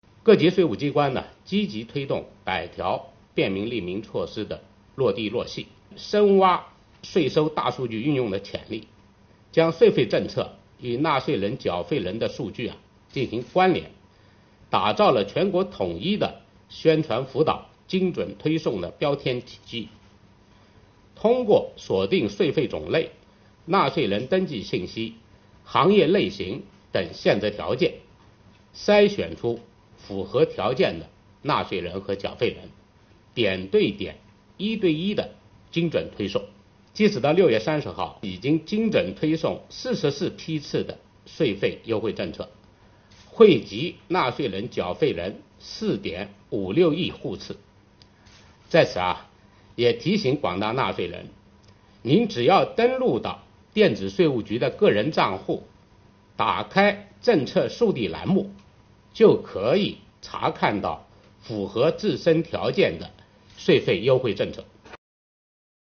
近日，国家税务总局召开新闻发布会，就税收大数据反映经济发展情况、税务部门学党史办实事扎实推进办税缴费便利化、打击涉税违法犯罪等内容进行发布并回答记者提问。会上，国家税务总局纳税服务司司长韩国荣介绍了“我为纳税人缴费人办实事暨便民办税春风行动”取得的进展和成效。